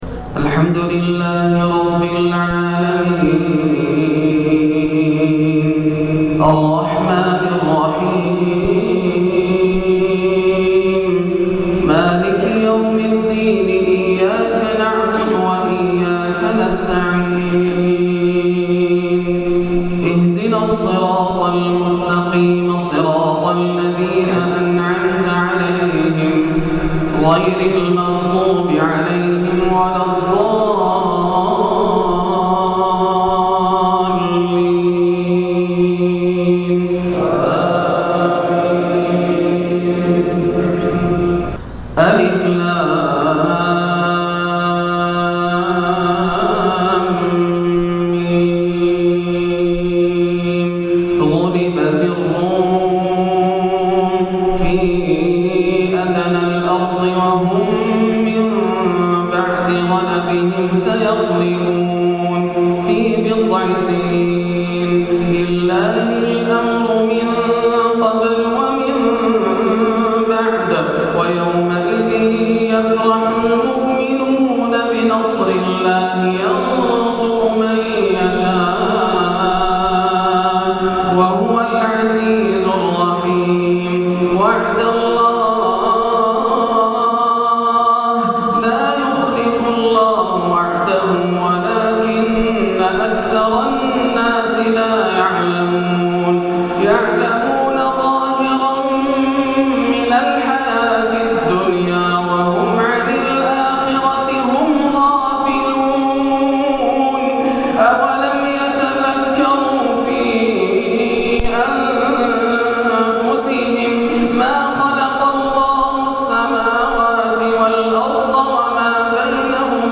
أول سورة الروم حزين- شهر 7 أو 8 - 1431 > عام 1431 > الفروض - تلاوات ياسر الدوسري